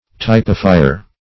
Typifier \Typ"i*fi`er\, n. One who, or that which, typifies.
typifier.mp3